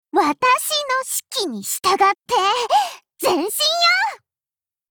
Cv-20510_warcry.mp3